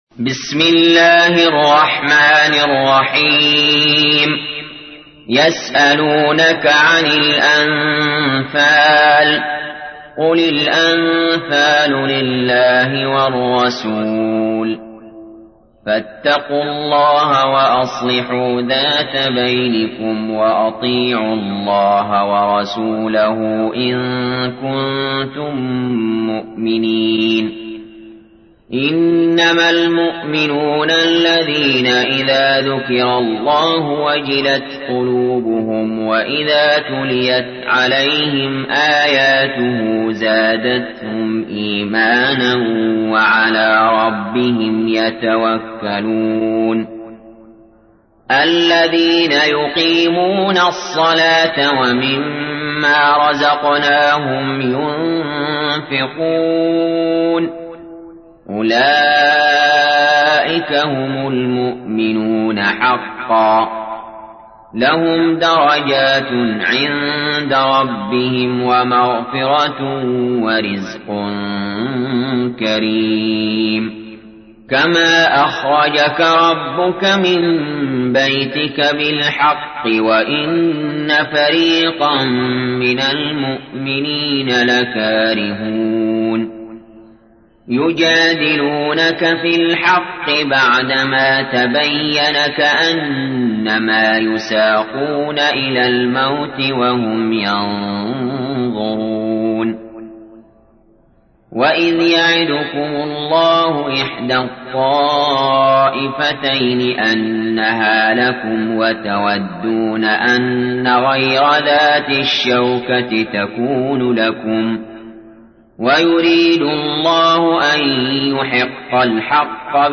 تحميل : 8. سورة الأنفال / القارئ علي جابر / القرآن الكريم / موقع يا حسين